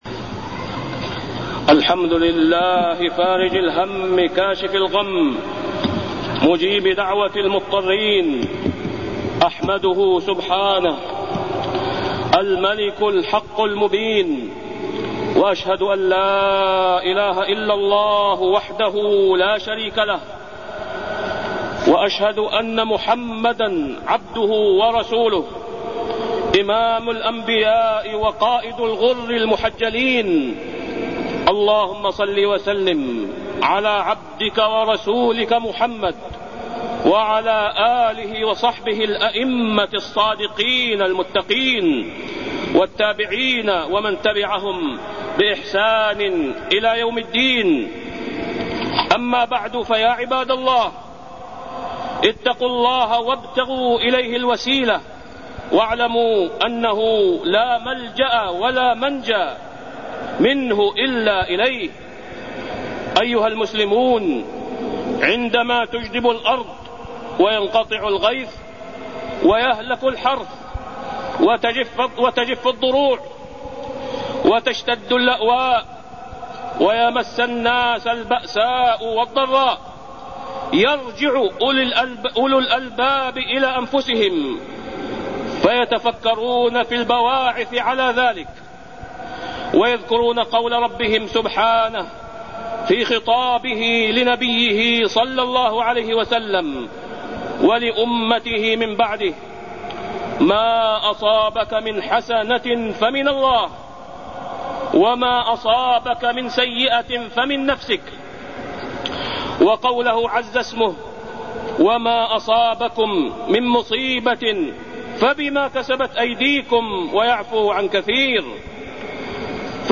تاريخ النشر ٦ ذو القعدة ١٤٢٢ هـ المكان: المسجد الحرام الشيخ: فضيلة الشيخ د. أسامة بن عبدالله خياط فضيلة الشيخ د. أسامة بن عبدالله خياط نصائح